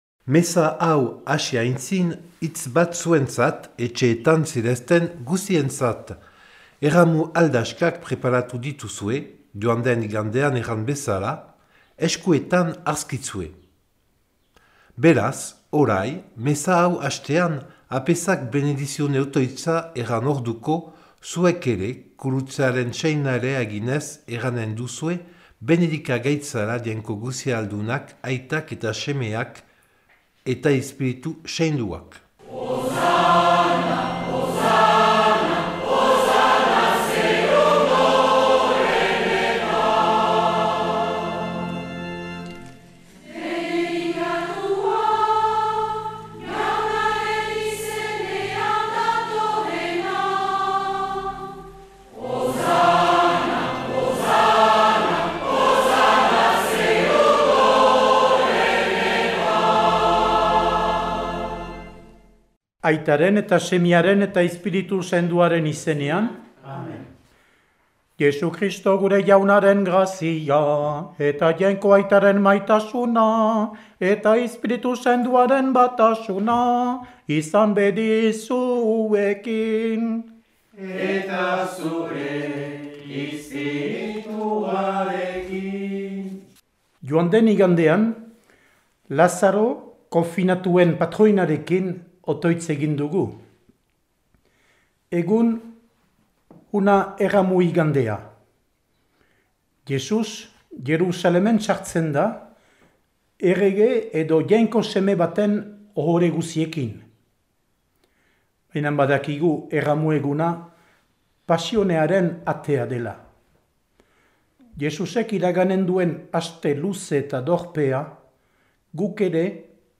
Igandetako Mezak Euskal irratietan